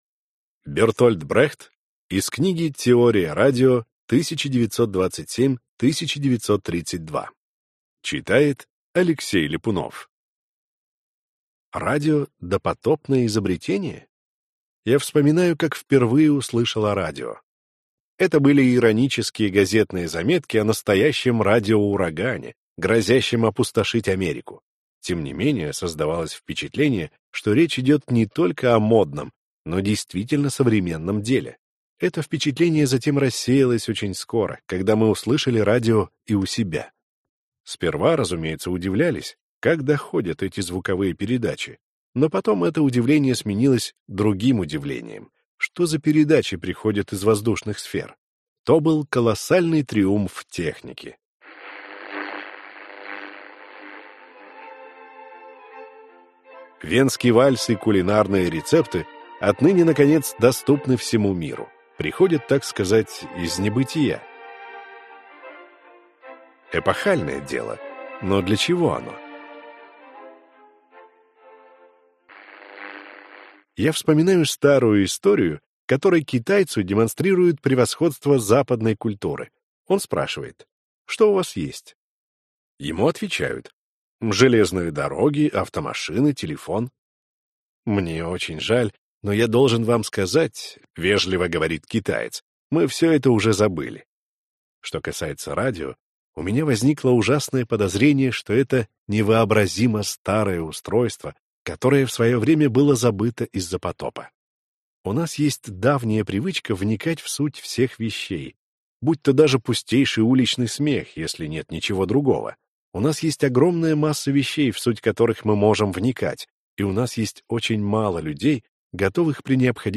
Аудиокнига Радио – допотопное изобретение?